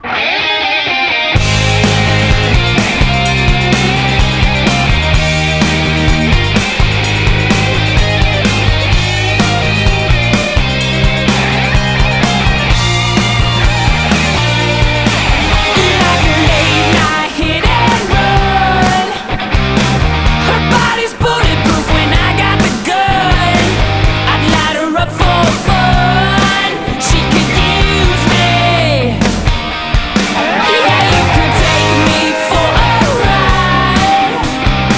Жанр: Рок / Альтернатива
Rock, Alternative